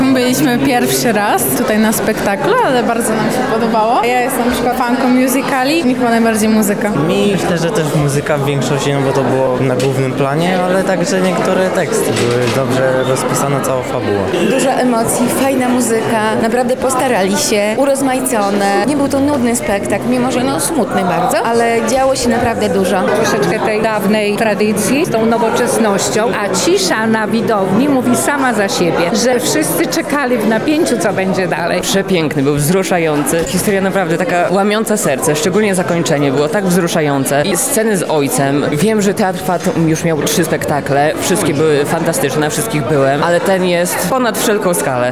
„Wianki” – relacja z premiery spektaklu
Opinie-widzow_01.mp3